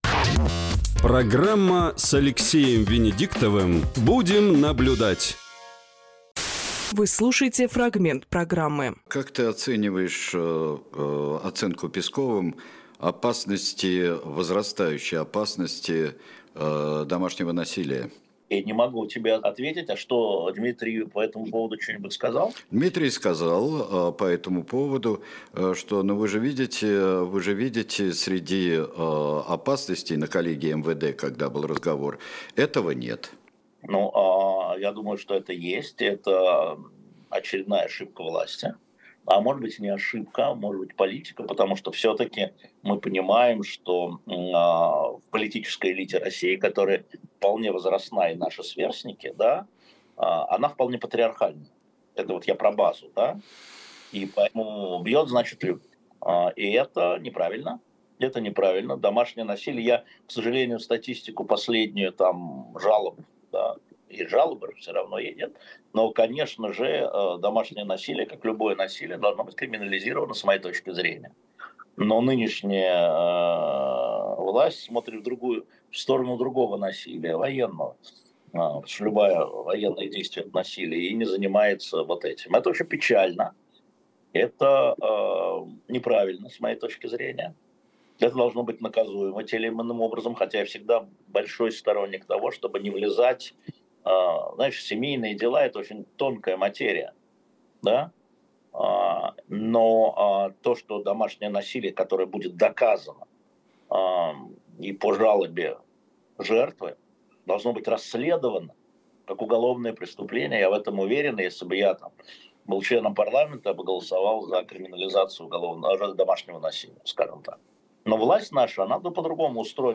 Фрагмент эфира от 20.04.24